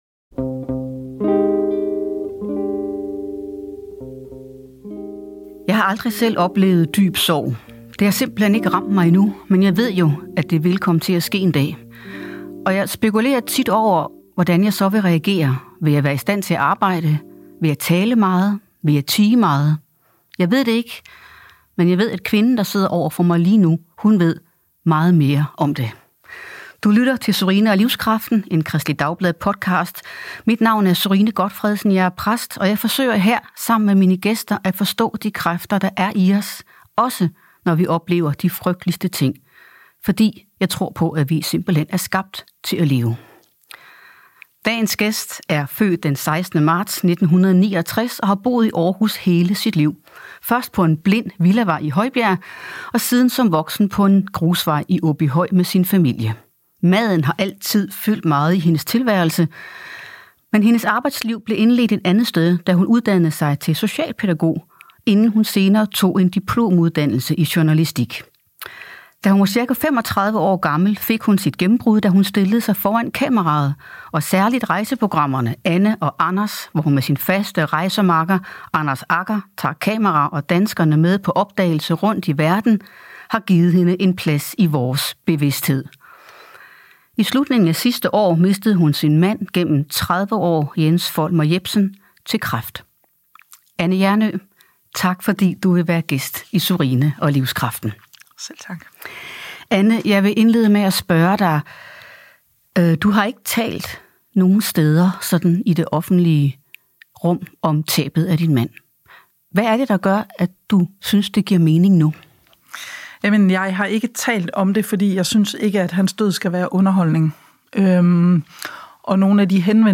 I denne podcastserie taler hun i hvert afsnit med en gæst, der deler en personlig fortælling om at finde livskraften i et definerende øjeblik.